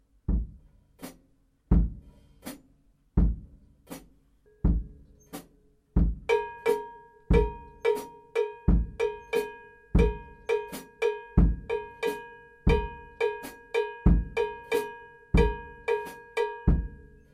Eine zweitaktige Grundfigur, ein Takt enthält zwei, einer drei Schläge.
Und so klingt die 2-3-Son Clave.
zwei-drei-son-clave.mp3